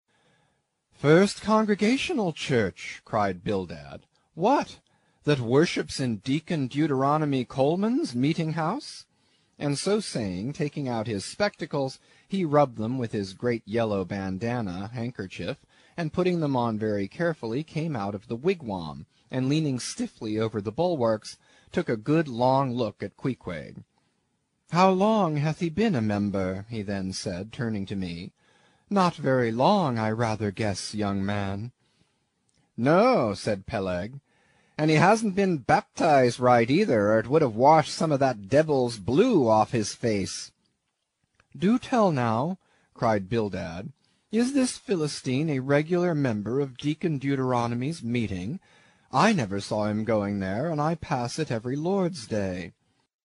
英语听书《白鲸记》第96期 听力文件下载—在线英语听力室